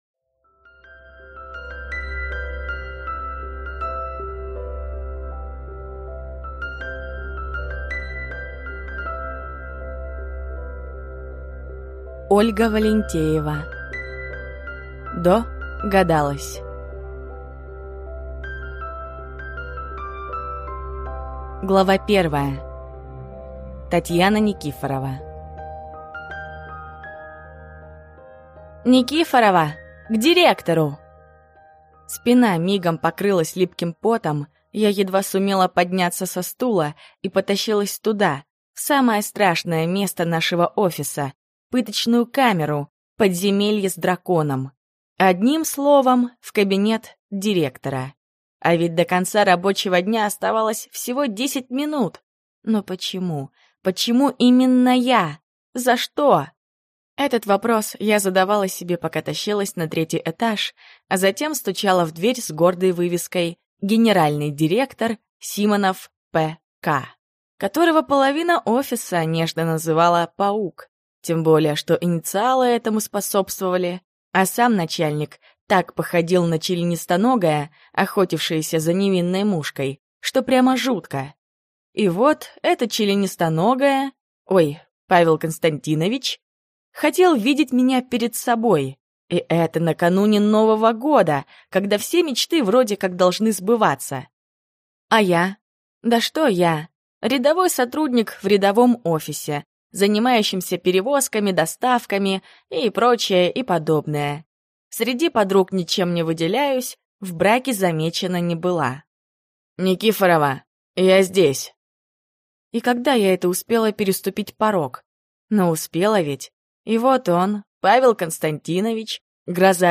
Аудиокнига ДоГадалась | Библиотека аудиокниг